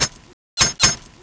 knife_stab.wav